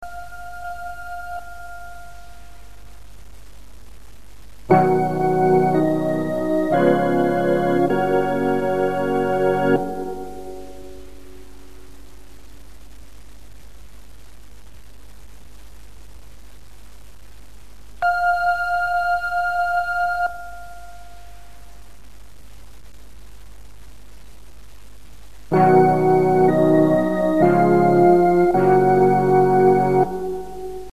Organ.
This is the music backing for when Brethren chant "So Mote It Be".